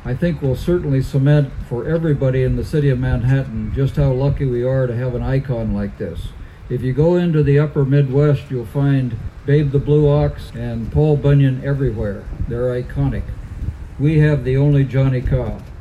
A speaker at the event was Manhattan Mayor Mike Dodson who said no tax dollars were used on the project.